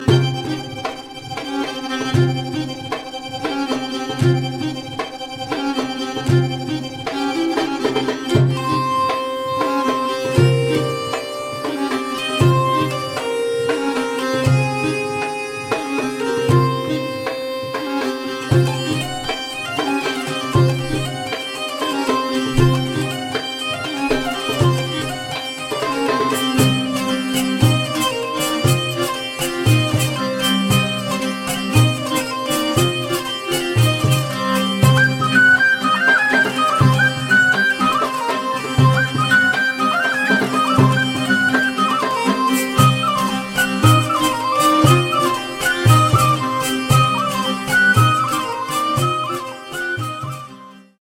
musiques traditionnelles et médiévales
chant, flûte, cromorne, vielle à roue
chant, flûte, cromorne, harpe romane
chant, nyckelharpa
bendir, darabukka, davul